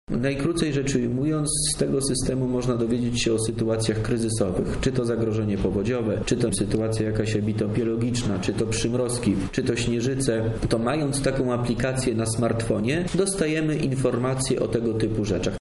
O szczegółach mówi Wojciech Wilk.